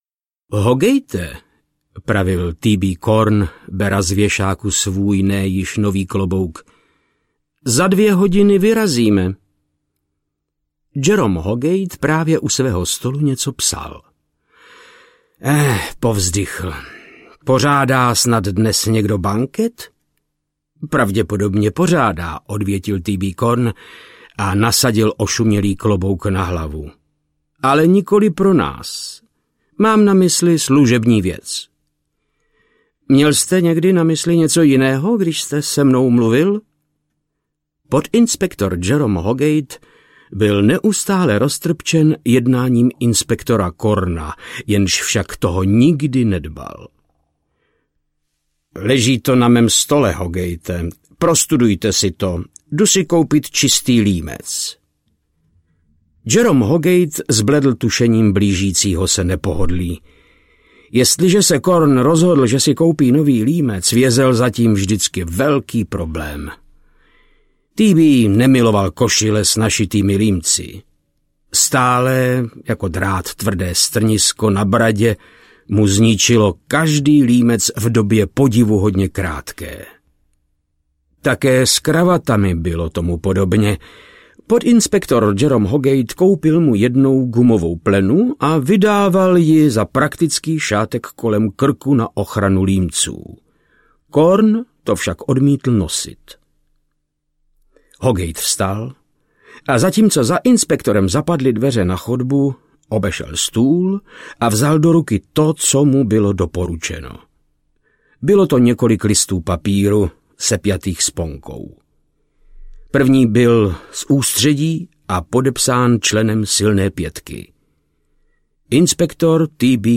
Paní z šedivého domu audiokniha
Ukázka z knihy
• InterpretVáclav Knop